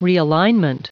Prononciation audio / Fichier audio de REALIGNMENT en anglais
Prononciation du mot : realignment